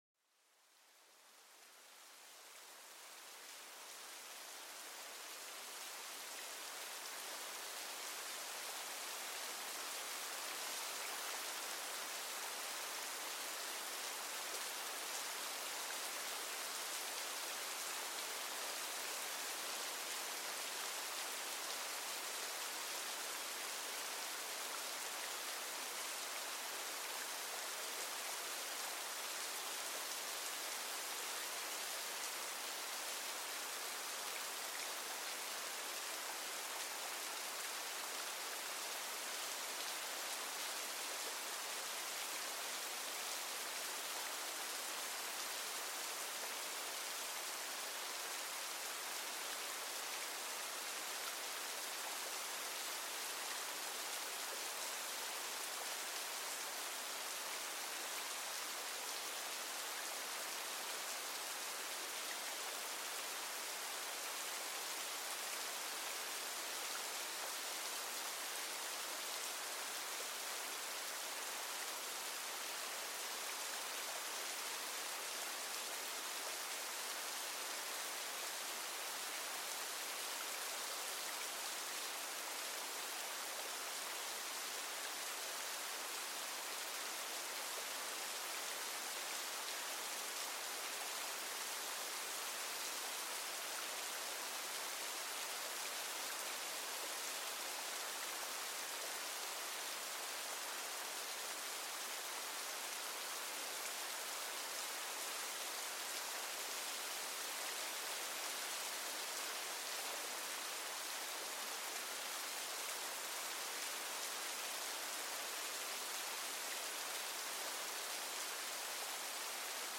Douceur de la Pluie: Mélodie Apaisante